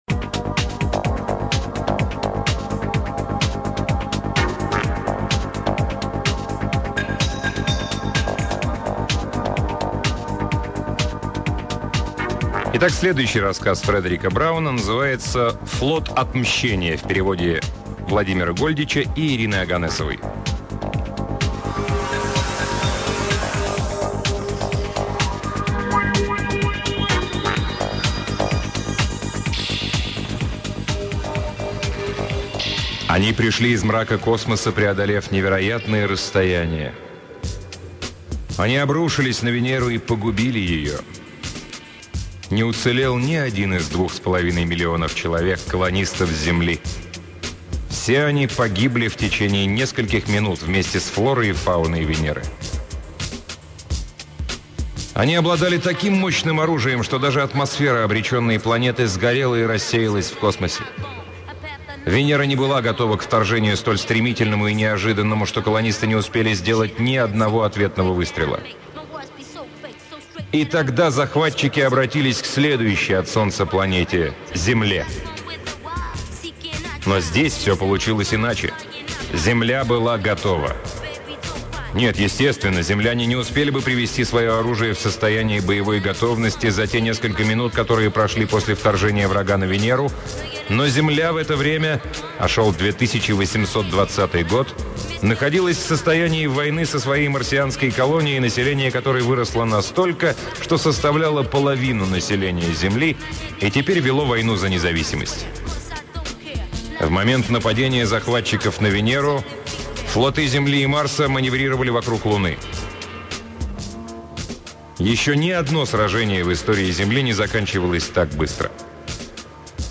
Аудиокнига Фредерик Браун — Флот отмщения